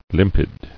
[lim·pid]